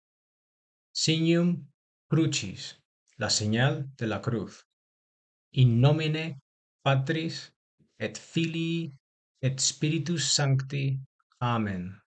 (Descarga el audio de cómo pronunciar el Signum Crucis)